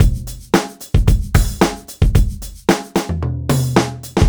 Track 15 - Drum Break 03.wav